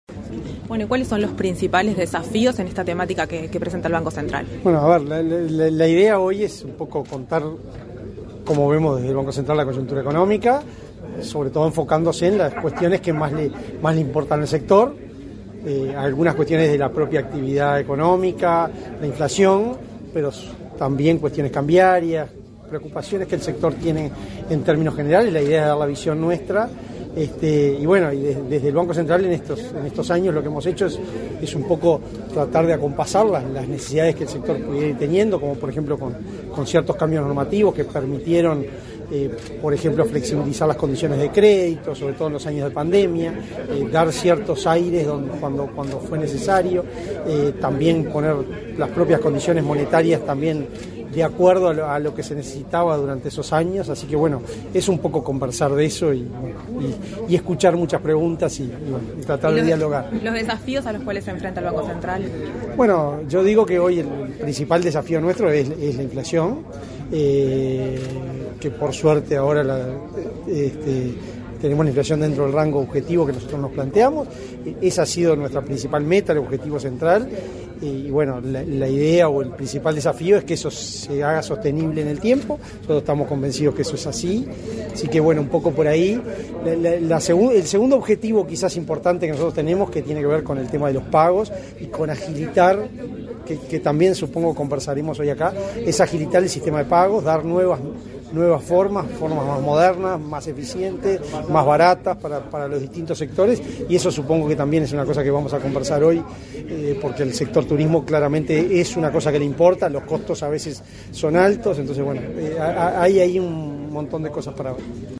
Entrevista al presidente del Banco Central, Diego Labat